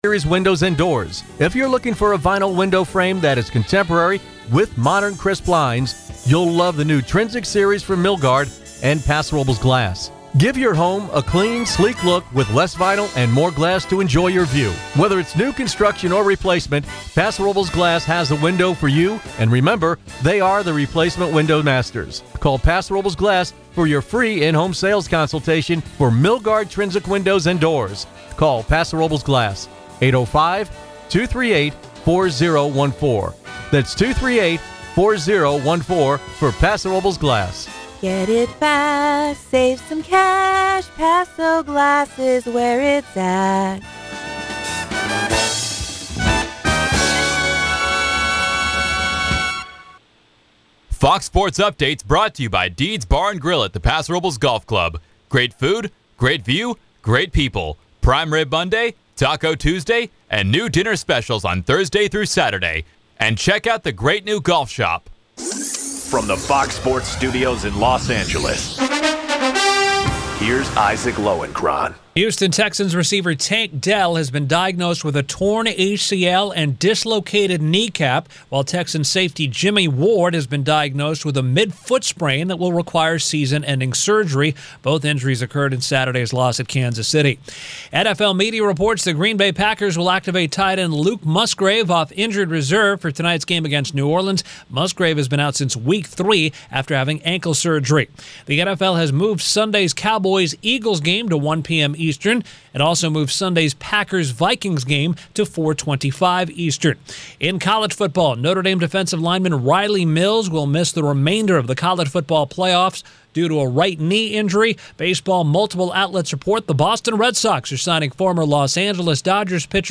Tune in to the longest running talk show on the Central Coast.